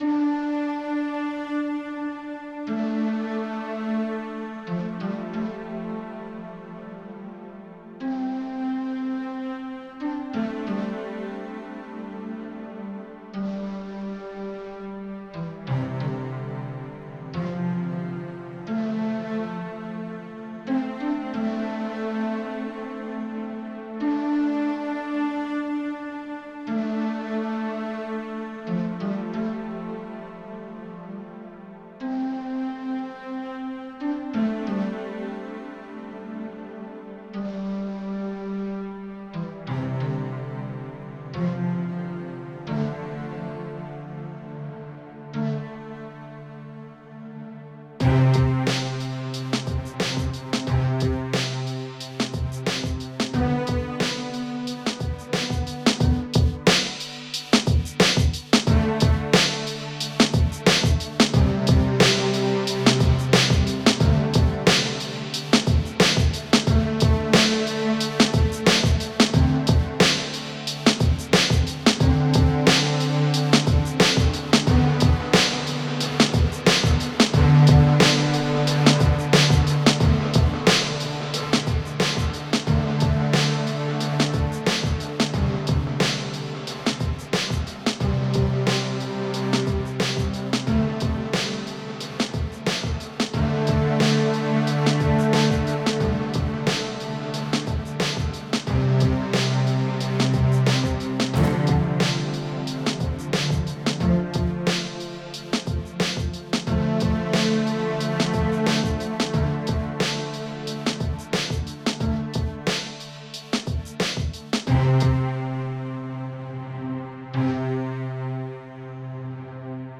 electronic music Archives
Strings-Section.mp3